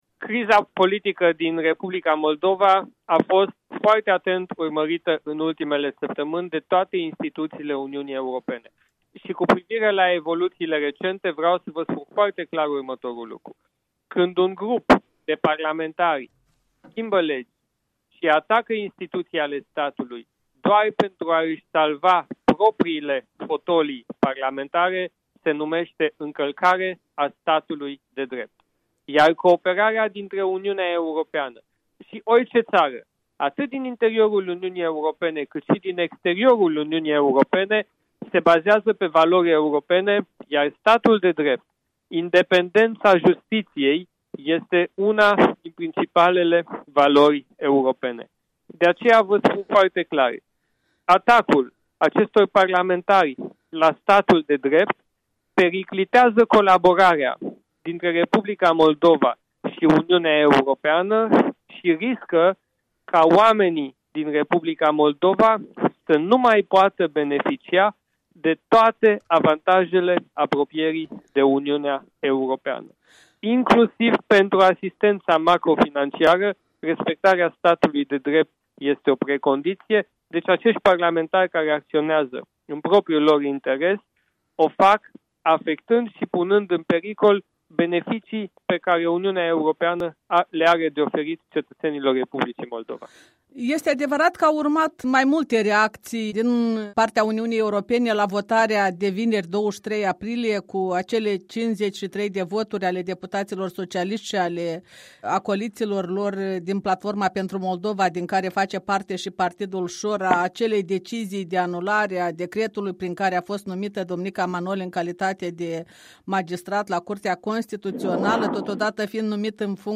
Interviu cu Siegfried Mureșan